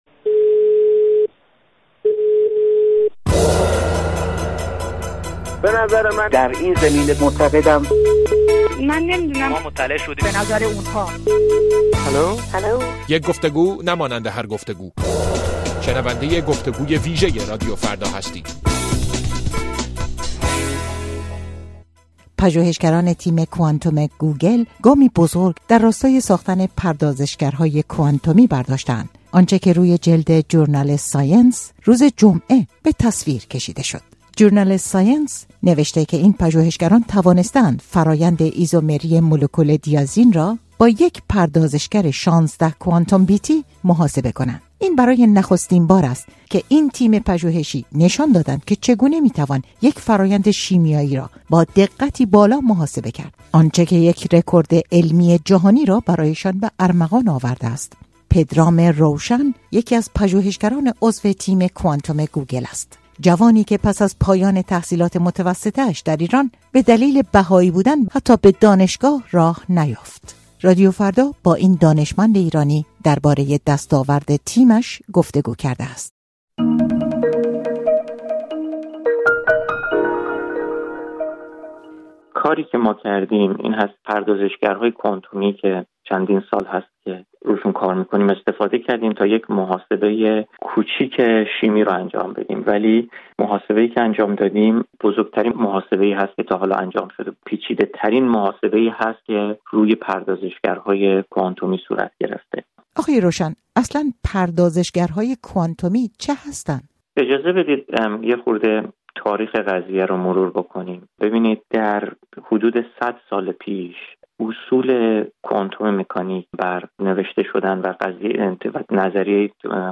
گفت‌وگوی ویژه: چطور پردازش کوانتمی داروسازی را متحول می‌کند؟